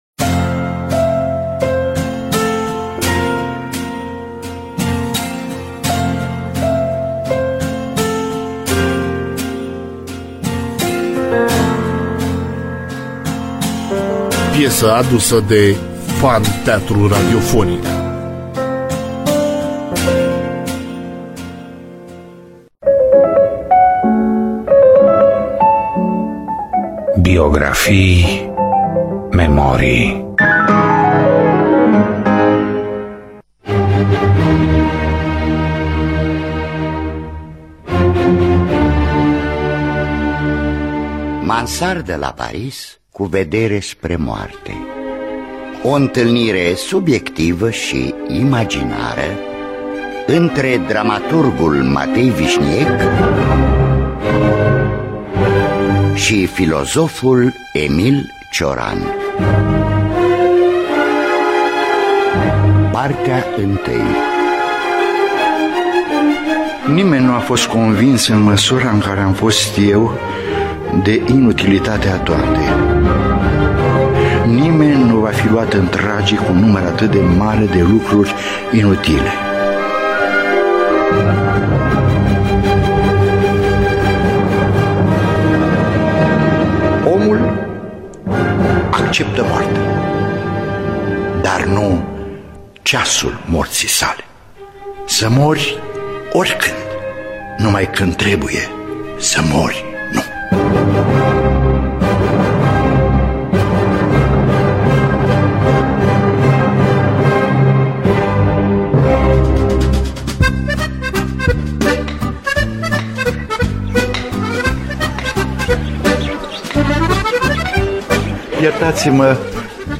Adaptarea radiofonică şi regia artistică